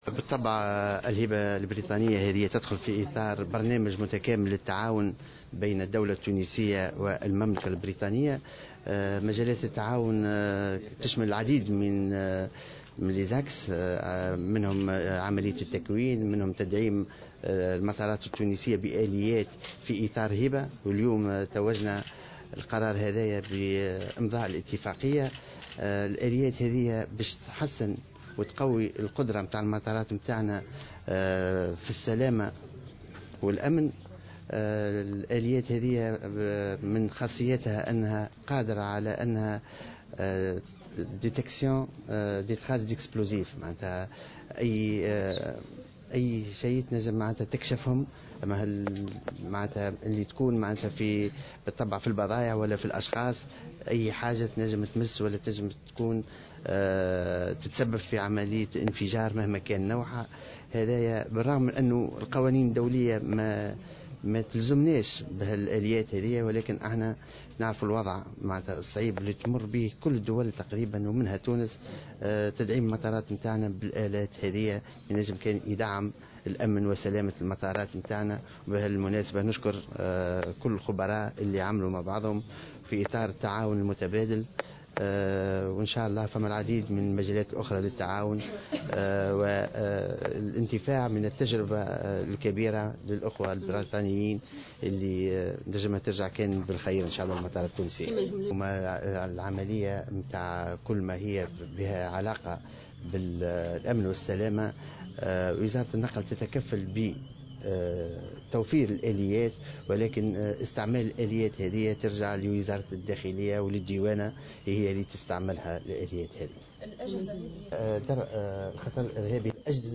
وقال في تصريحات صحفية إن هذا الحدث يأتي في اطار برنامج تعاون بين البلدين يشمل مجالي التدريب والآليات.